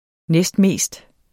næstmest adverbium Udtale [ ˈnεsdˈmeˀsd ] Betydninger mest næstefter det mest forekommende Se også mest Kaffe er verdens næstmest handlede vare efter olie Pol2014 Politiken (avis), 2014.